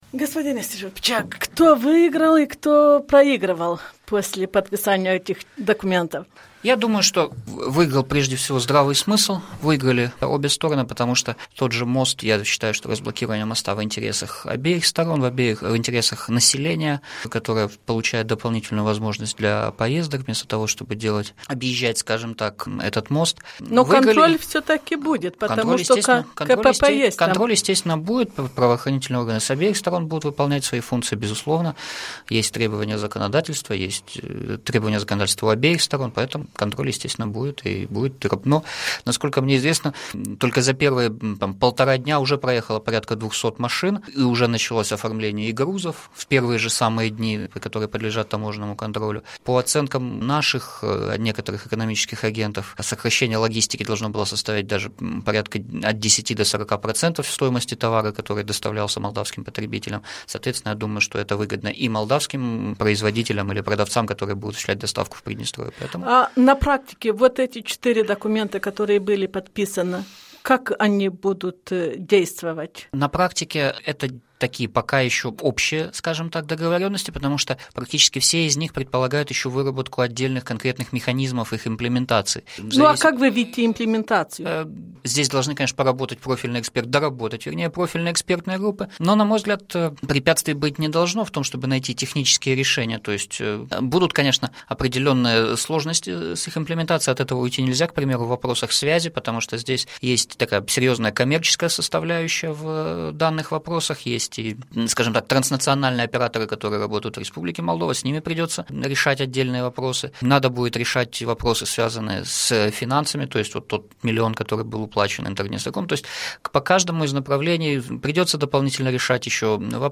Interviu cu Vladimir Iastrebceak